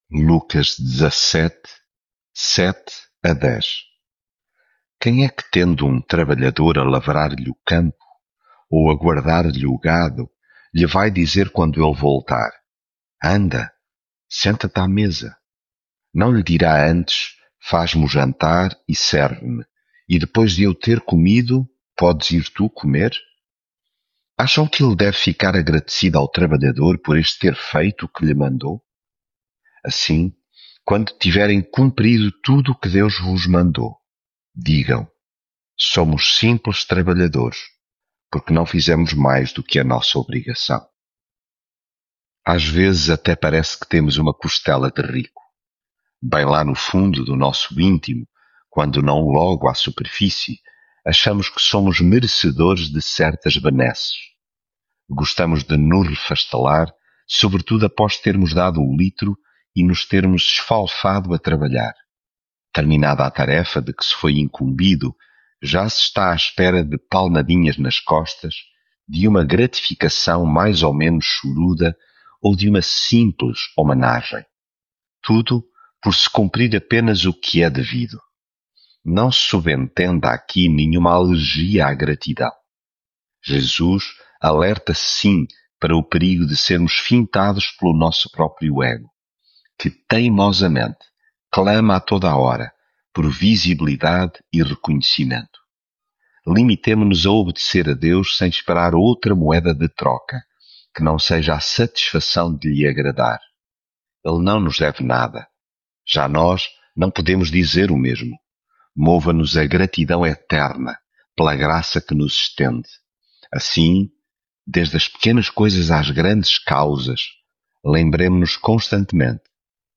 Devocional
leitura bíblica